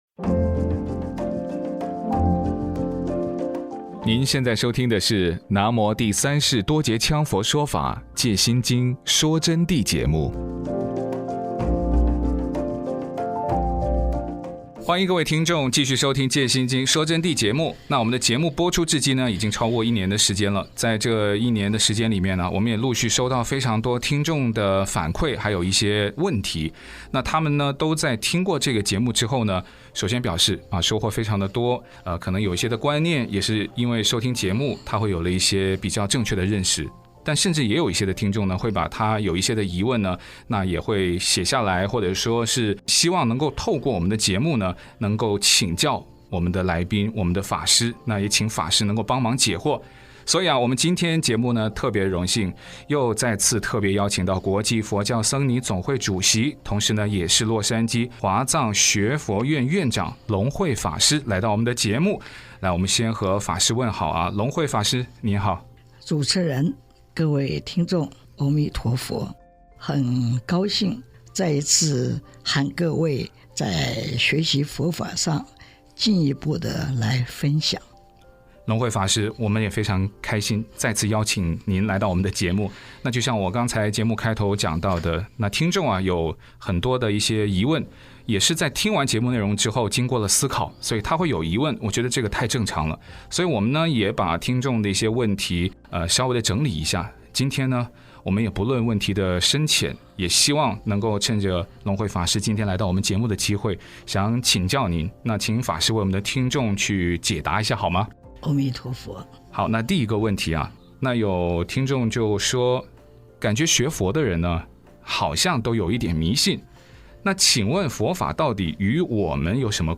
佛弟子访谈（六十八）佛法与我们有什么关系？一定要学佛吗？佛教是一种宗教吗？如何解开命运的枷锁？